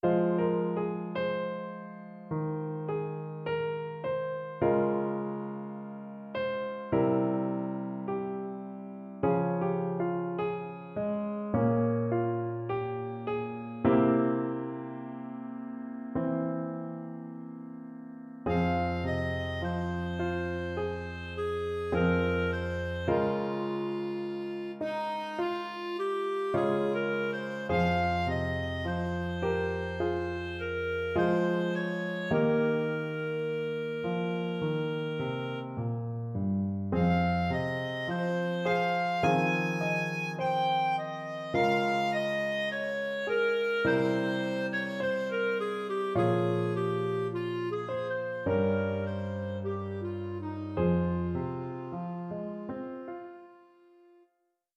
Instrumentation: Bb Clarinet, Piano